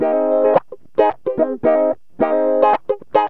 GTR 17A#M110.wav